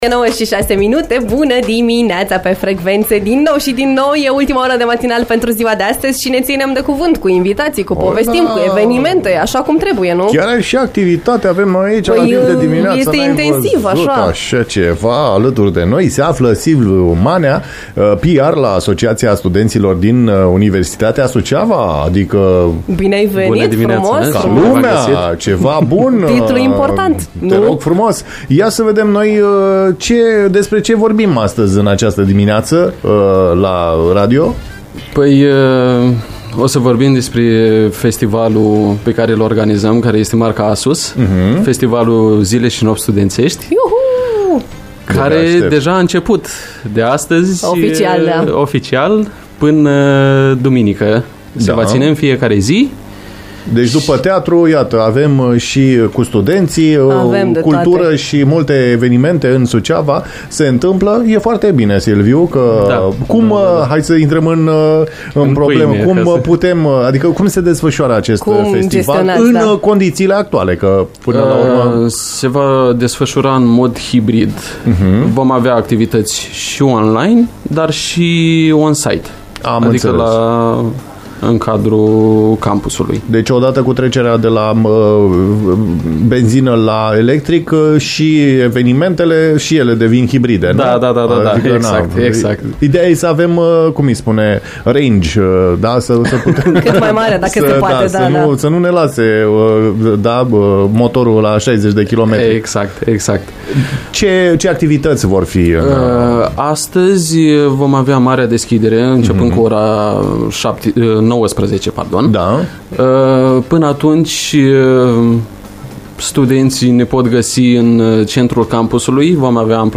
Astăzi începe, la Universitatea „Ștefan cel Mare” din Suceava, Festivalul Zile Și Nopți Studențești. În studio